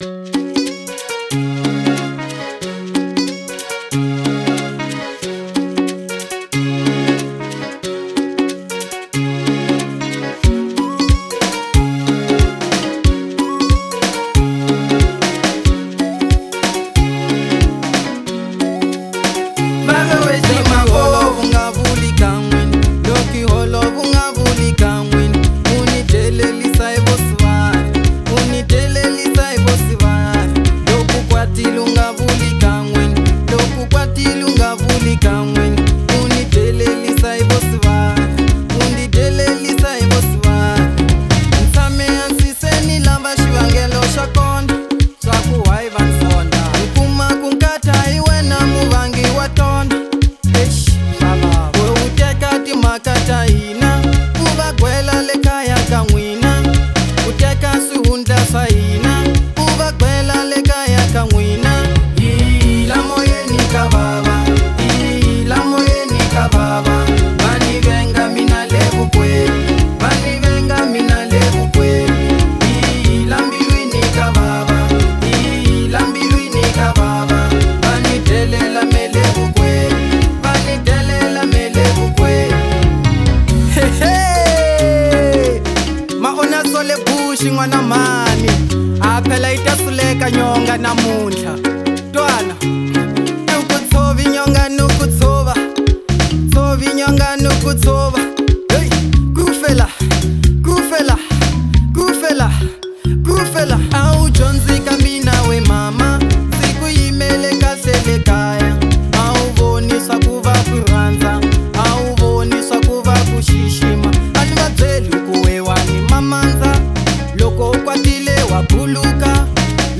Genre : Marrabenta